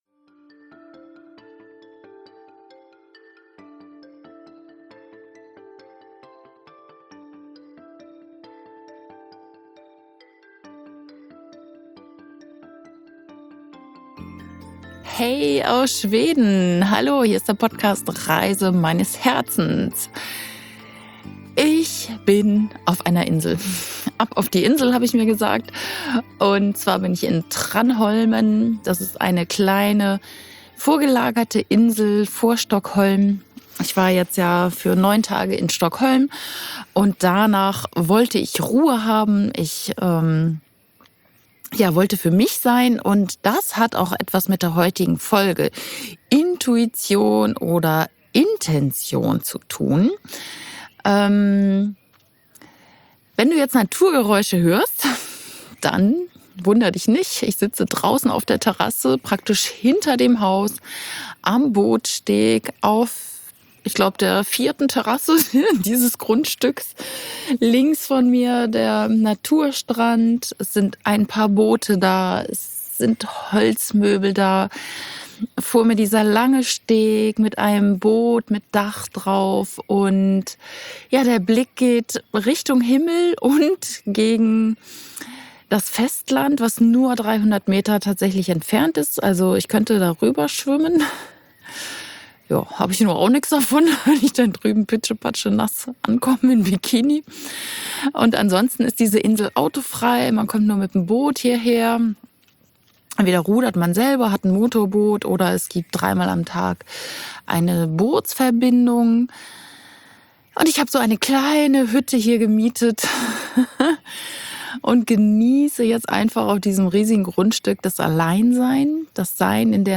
Aufgrund einiger Nachfragen von Podcast-Hörern erzähle ich dir heute, wie ich meine Auszeit finanziere. Passend, dass ich genau diese Episode auf Maui aufnehme.